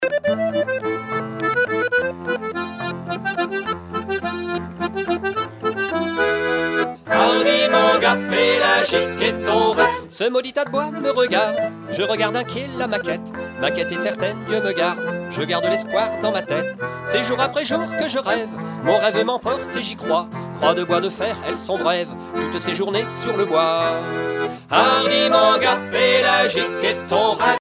danse : valse
Genre strophique
Concert donné en 2004